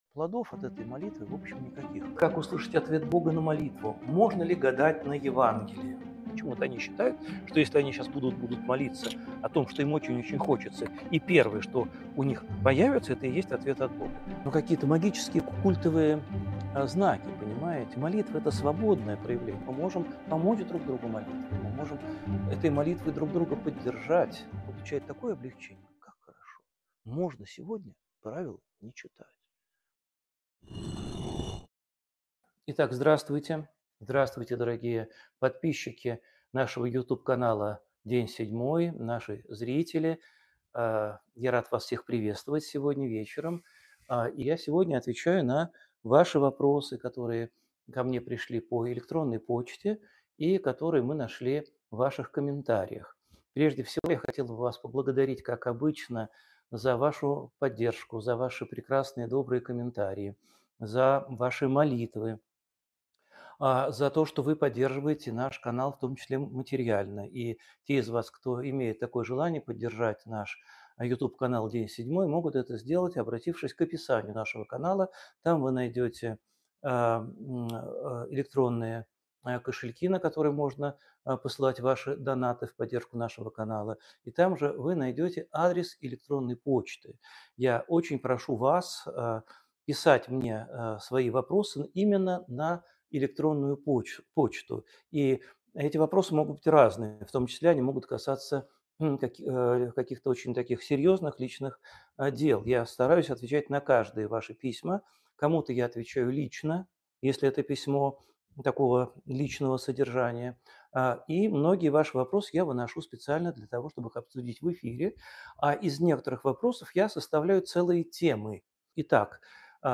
Эфир ведёт Алексей Уминский
Alexey Uminskiy Подборка из 15 ответов на вопросы о молитве, которые звучат на прямых эфирах. Почему нам не хочется молиться?